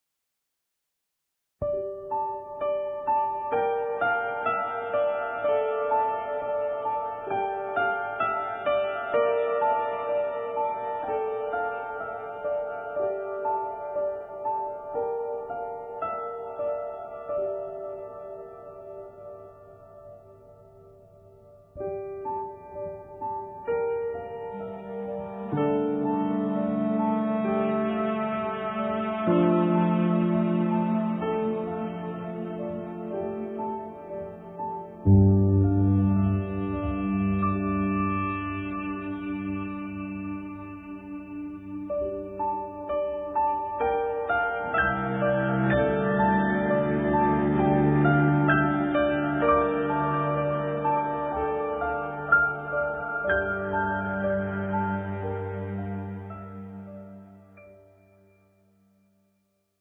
闇夜に煌めくピアノの旋律
ストリングス、ドローンと
ピアノが幾重にも重なり、
幻想的な曲調に魅了されます。